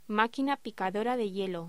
Locución: Máquina picadora de hielo
voz